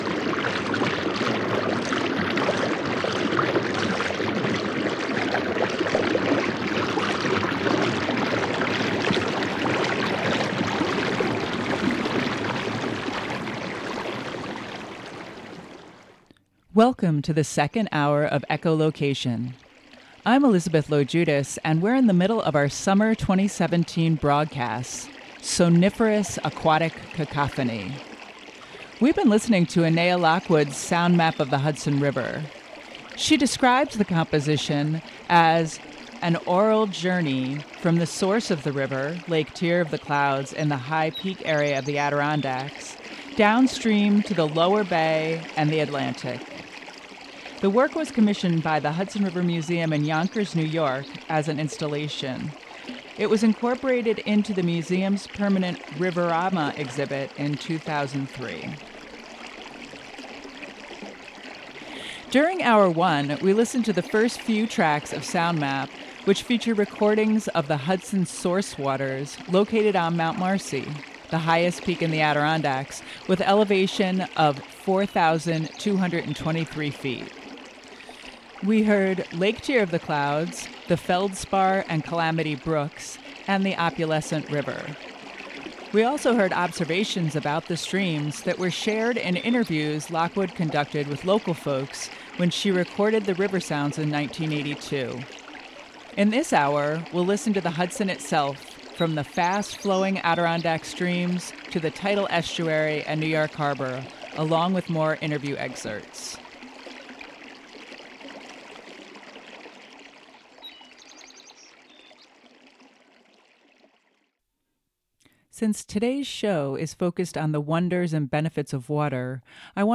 Hang out near the water and enjoy the summertime sounds that abound both above and below the surface. The Earth’s soniferous aquatic symphony includes the calls of echolocating electric eels, amorous fish, and the voice of the water itself – ranging from a gurgle to a roar.